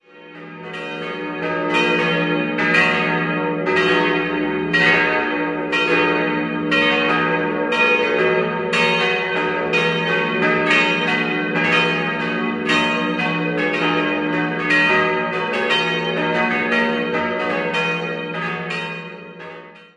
4-stimmiges Geläut: es'-ges'-as'-b' Die große Glocke stammt noch aus dem ersten Geläut und wurde 1895 von Bachmair in Ingolstadt gegossen, die zweite ist von der Gießerei hahn aus dem Jahr 1949, die dritte von Ulrich (Kempten) und die kleine von Hamm (Kaiserslautern) aus dem Jahr 1874.